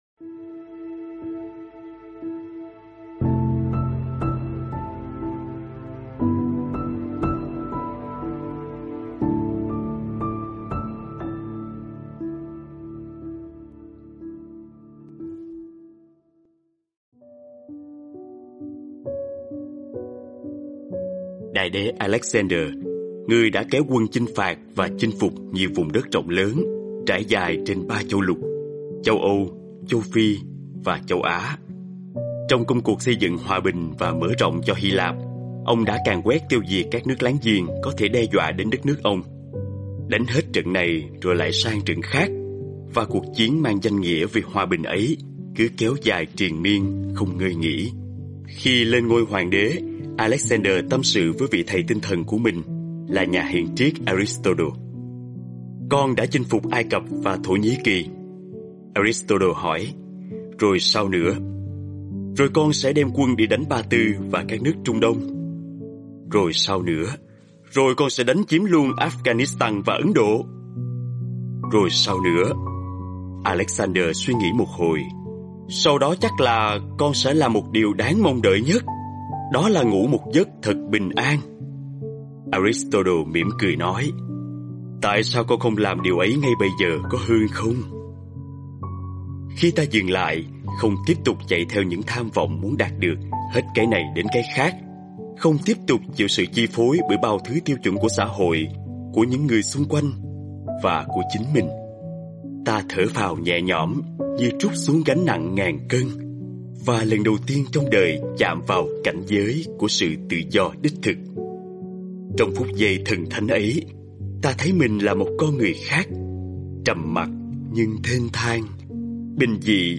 Sách nói mp3 Vẻ đẹp của sự dừng lại - TS.